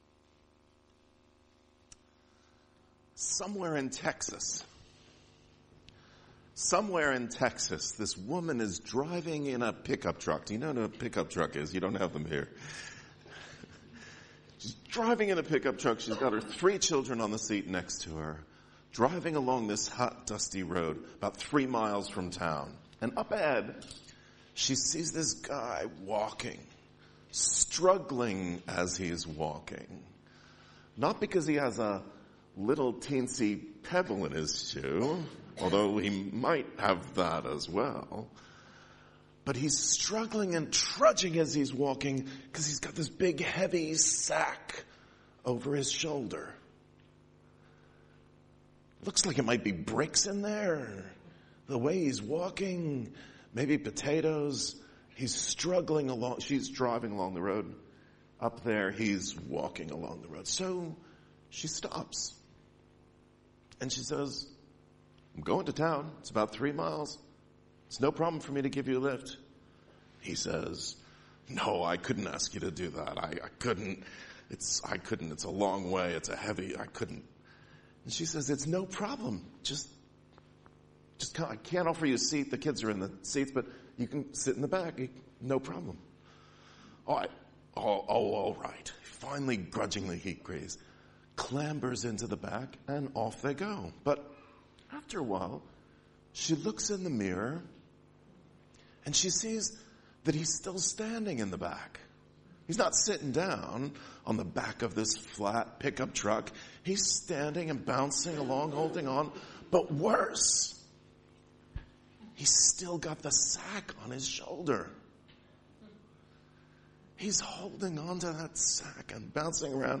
Media for Sunday Service on Sun 05th Jul 2015 11:00
Theme: Sermon